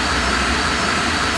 jet engine sound
jet.ogg